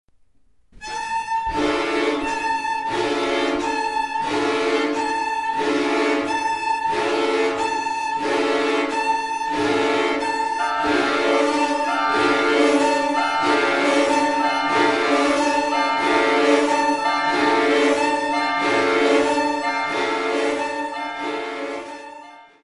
for old and new instruments